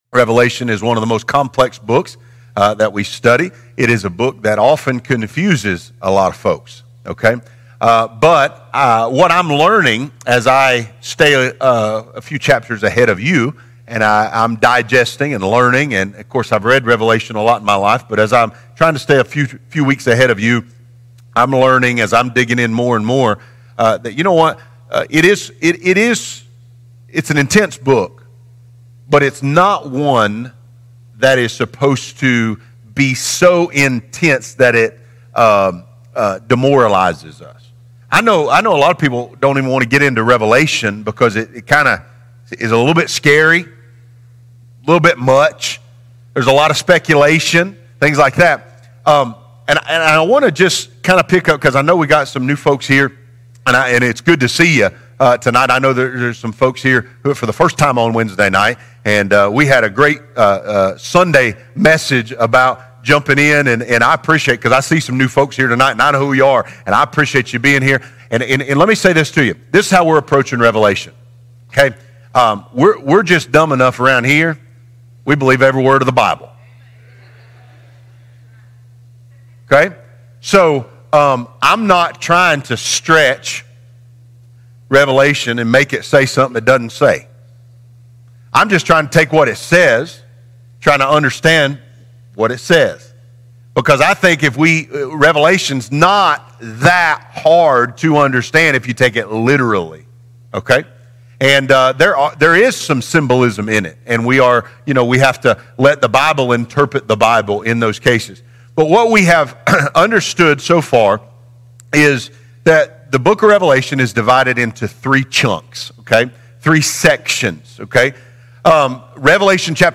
Have you ever wondered what the book of Revelation really means and how it relates to the current times? This is a verse-by-verse, bite-by-bite, in-depth Bible study that does not speculate or guess; letting Scripture speak and authenticate this important, but often overlooked book in the Bible.